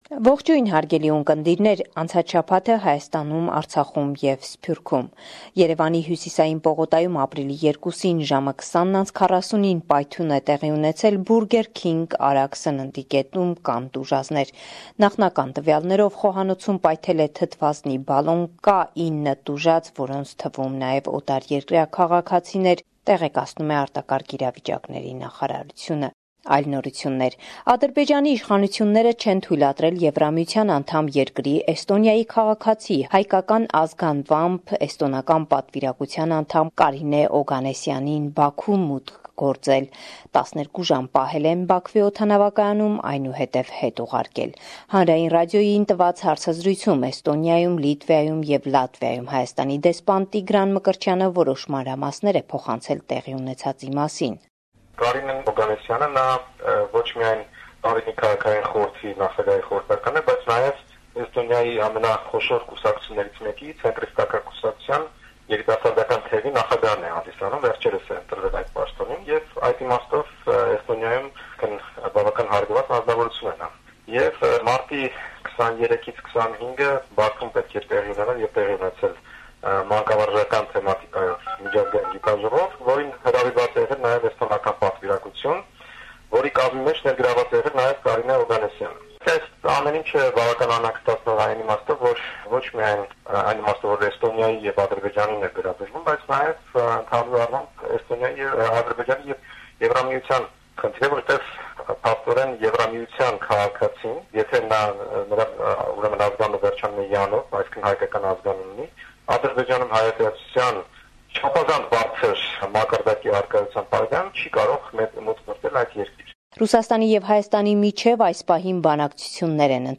Վերջին Լուրերը – 3 Ապրիլ 2018